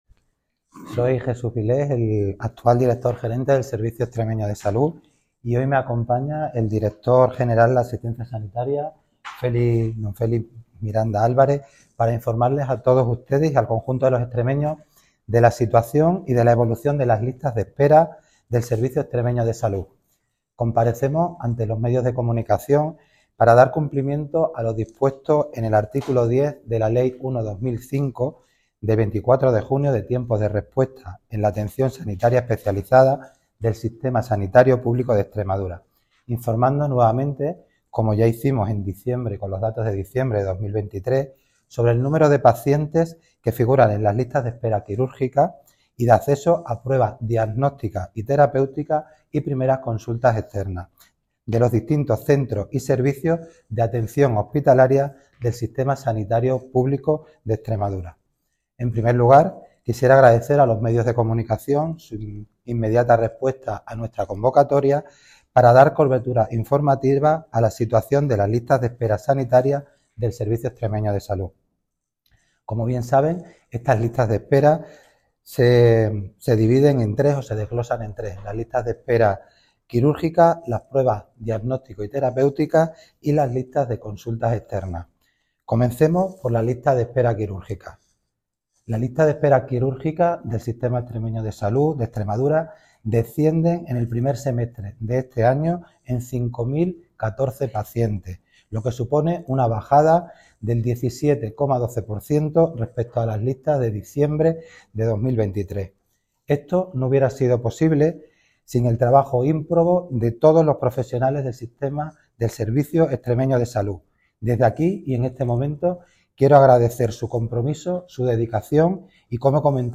Audio de la rueda de prensa .